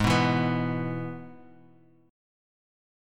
G#m#5 chord